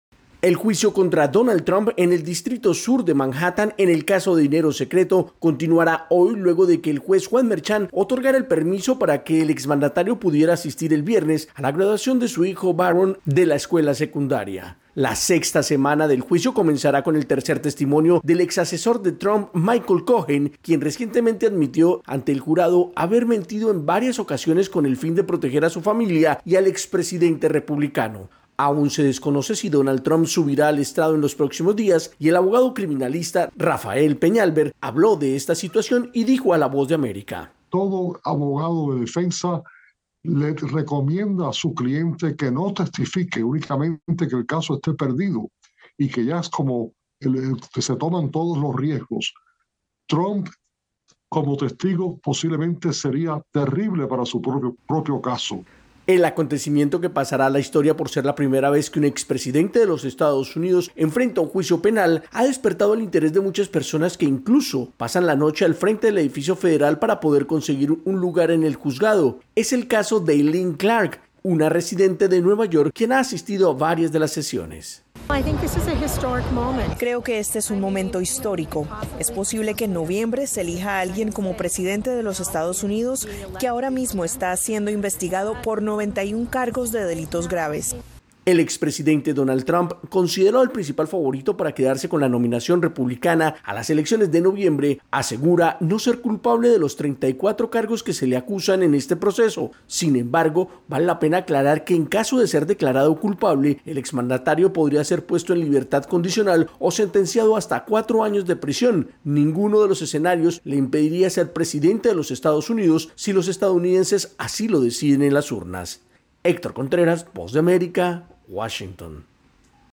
desde la Voz de América en Washington, DC.